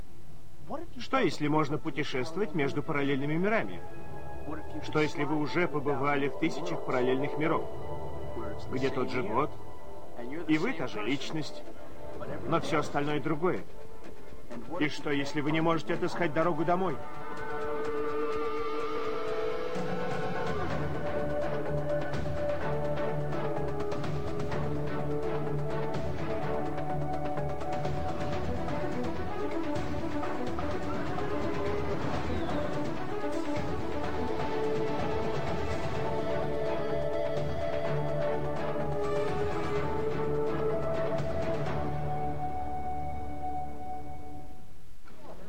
Der Introtext der dritten Staffel wird von einem Sprecher in die russische Sprache übersetzt. Man kann in dieser ersten Version ganz leise noch die englische Fassung hören.